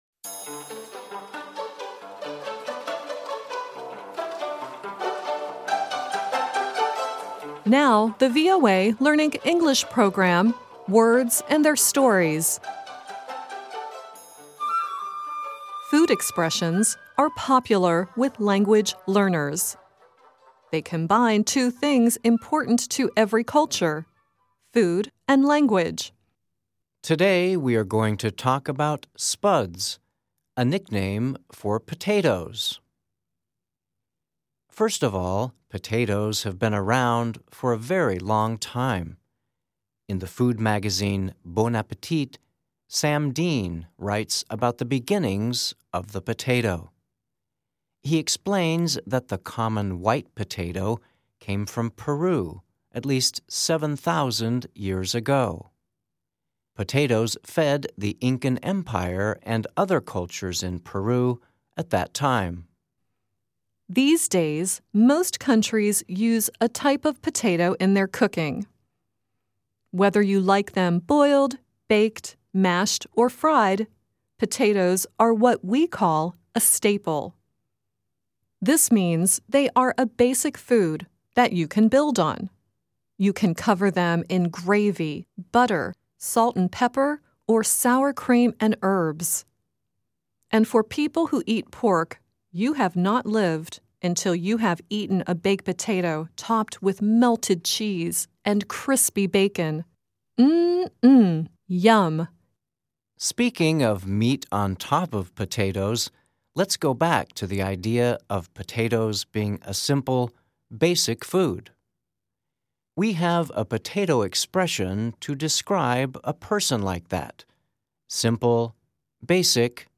Earlier in the program, Alan Jackson sings “Meat and Potato Man.” The song at the end of the program is the theme song to the children's show "Small Potatoes."